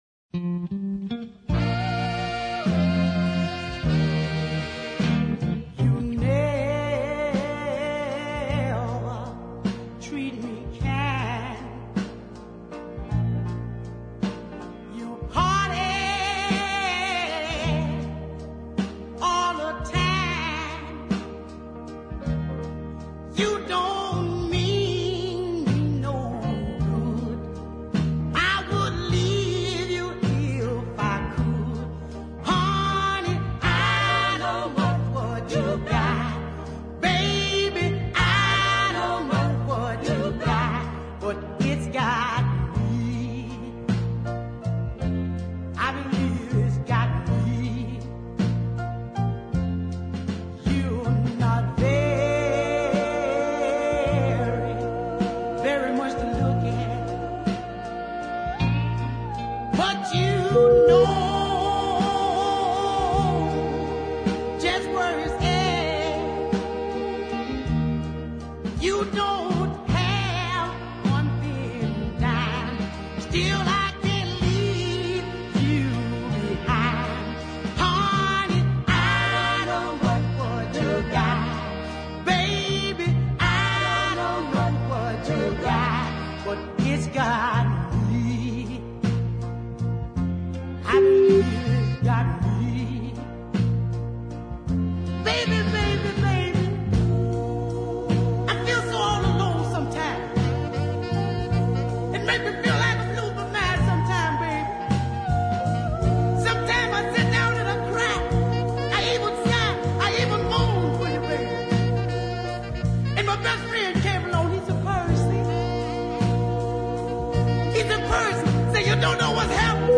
deep soul classic performed Memphis style